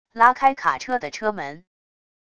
拉开卡车的车门wav音频